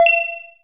ui_status_alram01.wav.wav